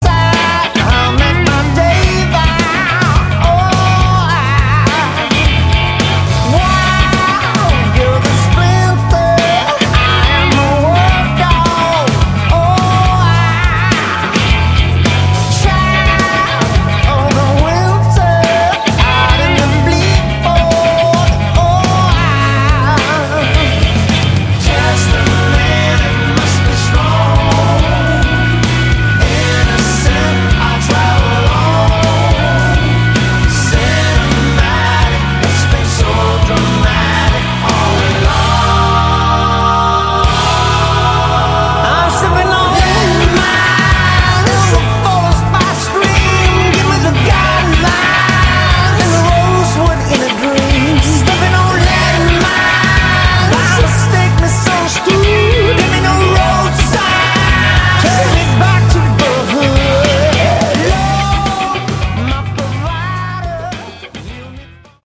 Category: Funky Hard Rock